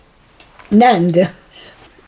Nînde/? [a ˈnɛnda, ˈnɛ̃da, ˈnɛ̃nda]; dt. Neind) ischt a Munizipalgmeind im Bezirk Conthey im Kanton Wallis, Schwiiz.